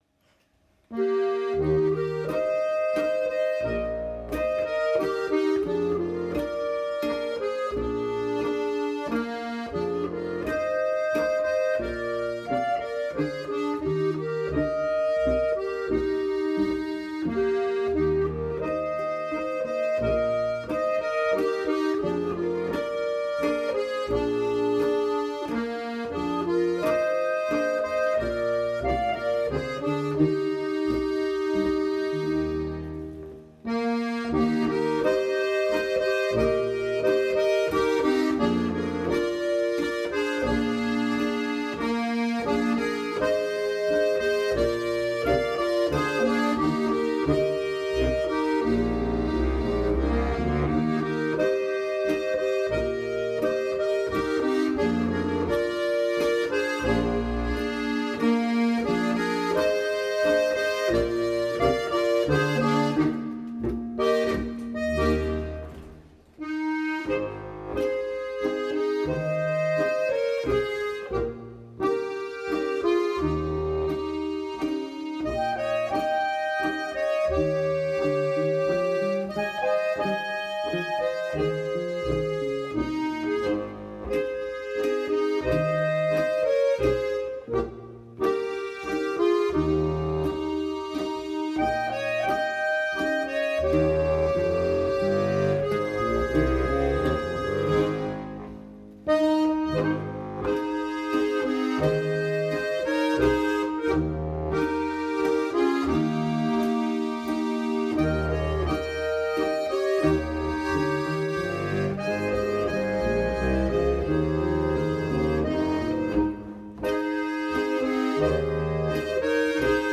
Ein langsamer Walzer für besondere Anlässe!